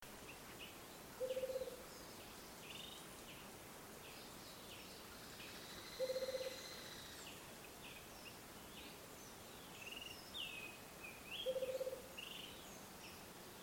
Rufous-capped Motmot (Baryphthengus ruficapillus)
Location or protected area: Parque Provincial Cruce Caballero
Condition: Wild
Certainty: Recorded vocal